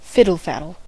fiddle-faddle: Wikimedia Commons US English Pronunciations
En-us-fiddle-faddle.WAV